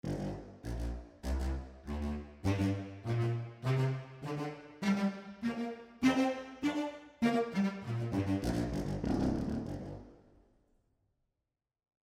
Sie hören die Bass Winds:
Zum Einsatz kam lediglich die Nah-Mikrofonierung. Den zusätzlichen Faltungshall habe ich ausgeschaltet. Trotzdem ist deutlich die Raumakustik in Form einer Hallfahne wahrzunehmen.